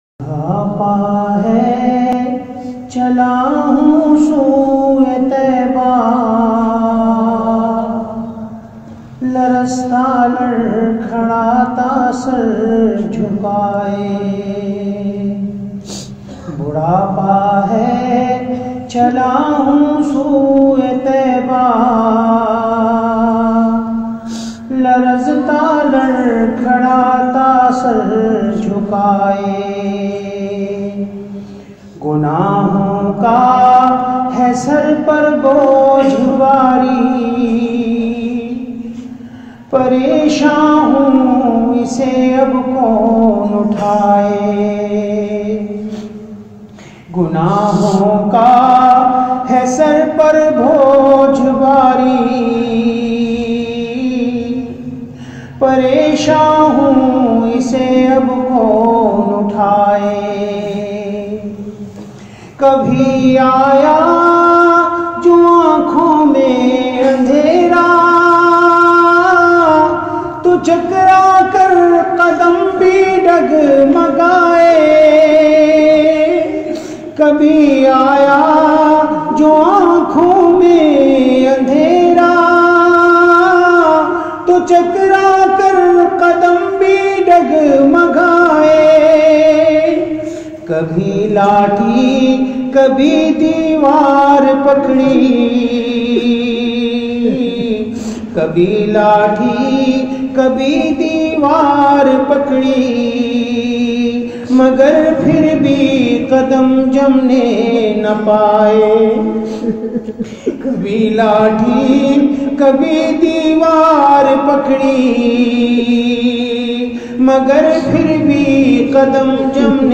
Heart-Touching Voice